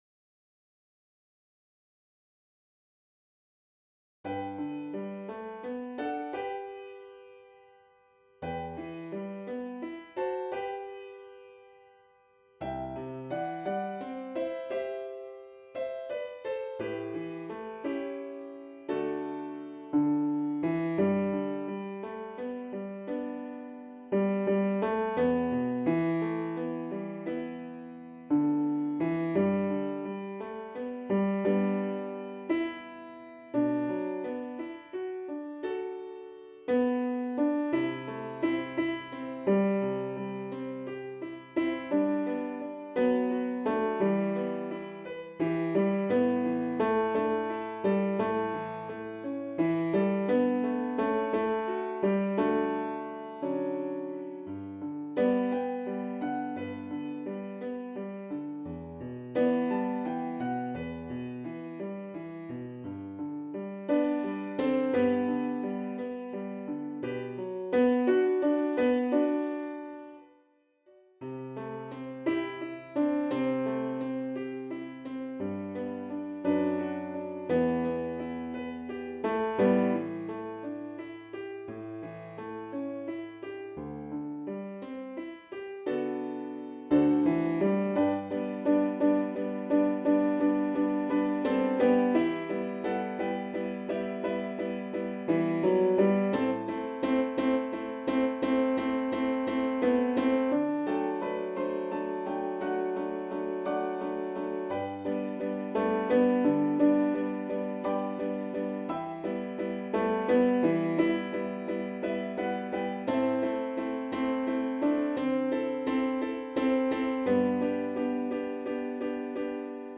Turn_Around_Again_Tenor_v2.mp3